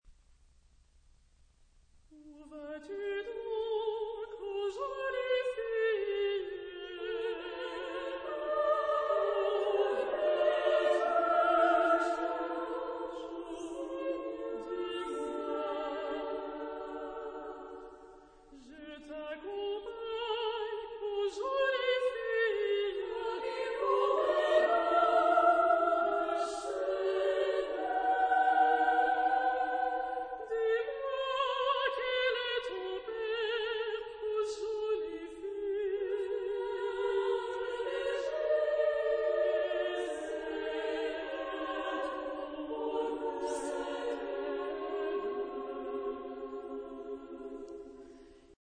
Genre-Stil-Form: zeitgenössisch ; Liedsatz ; weltlich
Chorgattung: SSA  (3 Kinderchor ODER Frauenchor Stimmen )
Tonart(en): C-Dur